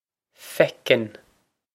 Feiceann Fec-n
This is an approximate phonetic pronunciation of the phrase.